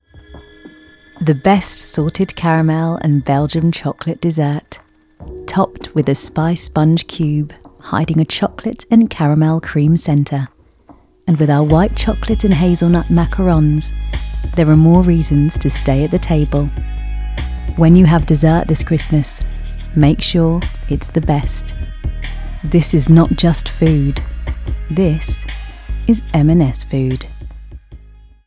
Smooth Cool Commercial
RP ('Received Pronunciation')